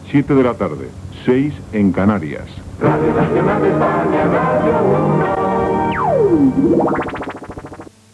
Hora i indicatiu de l'emissora com Radio 1.